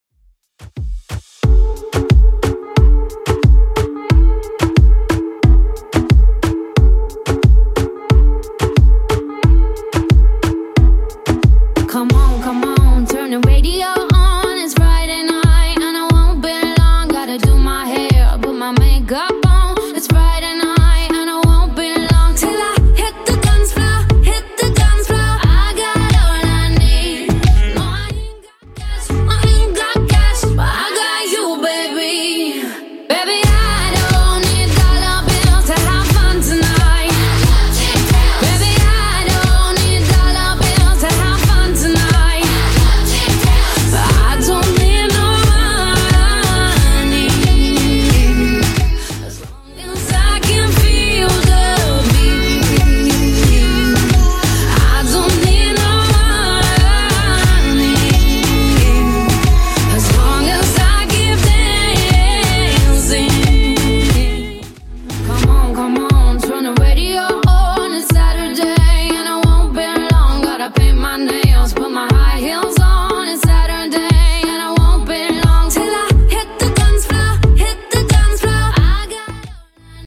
Genres: 2000's , RE-DRUM , TOP40 Version: Clean BPM: 115